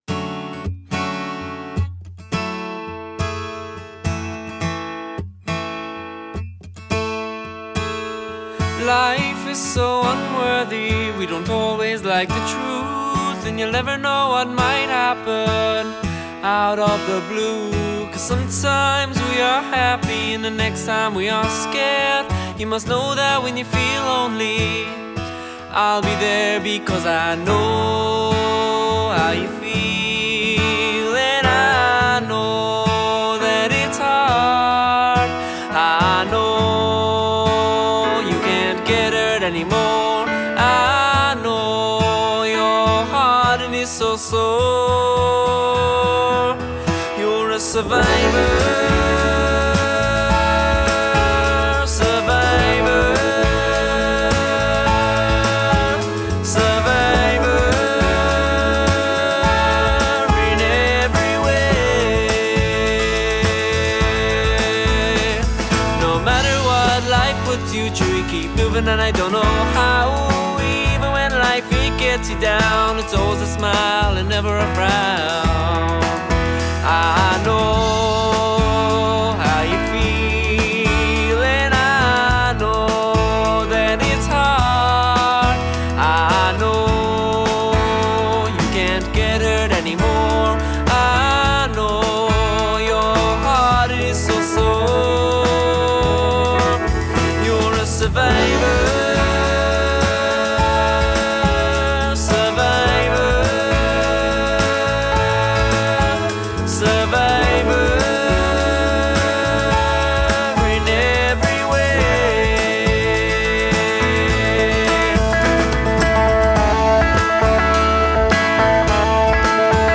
A hauntingly beautiful song
in Windmill Lane studio